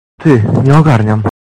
Worms speechbanks
hmm.wav